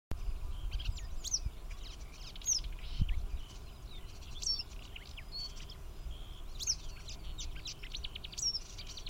желтая трясогузка, Motacilla flava
Administratīvā teritorijaOlaines novads
СтатусПоёт